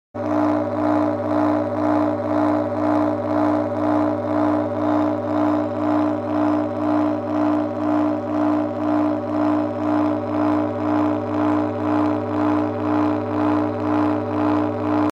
Grow your teeth back with frequencies